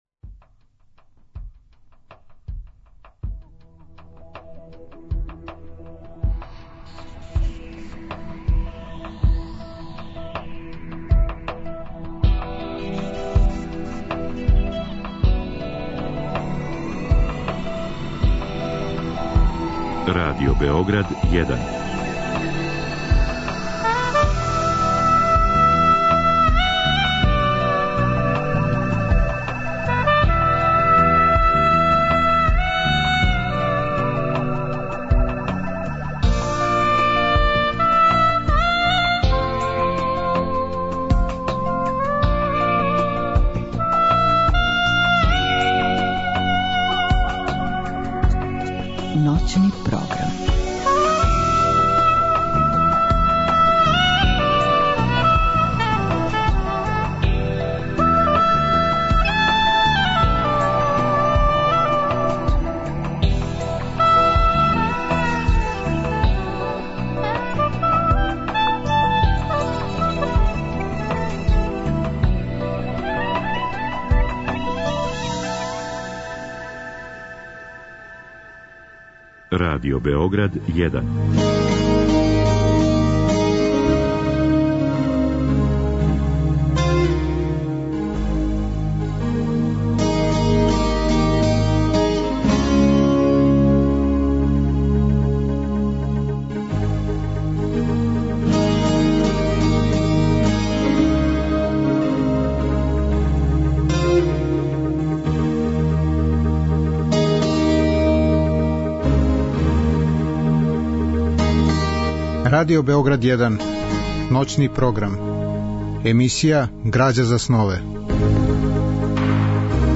Гост емисије је драмски уметник Милан Цаци Михаиловић. Он ће говорити о лепоти и изазовима глуме, и о многобројним улогама оствареним у позоришту, на филму, на радију и телевизији.
У другом делу емисије - од два до четири часа ујутро - као неку врсту мале личне антологије слушаћемо песме у избору и интерпретацији Милана Цација Михаиловића.